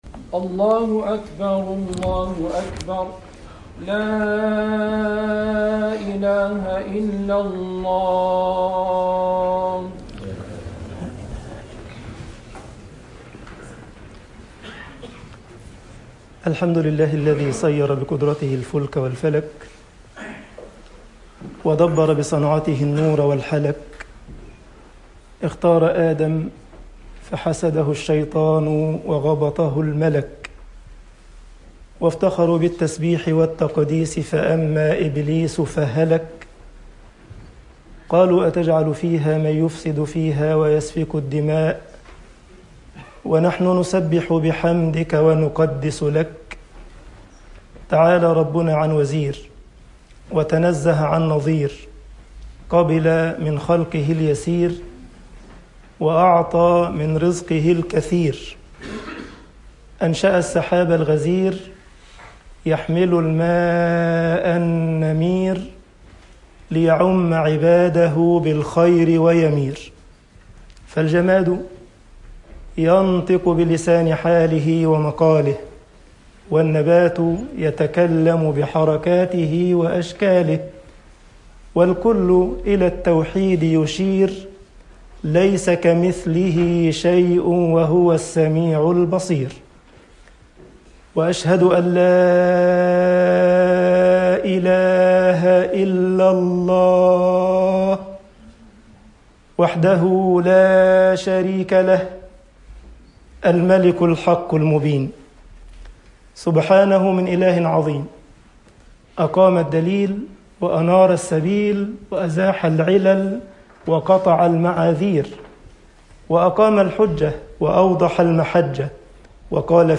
Freitagsgebet_al esmat min fitnat almansib 1.mp3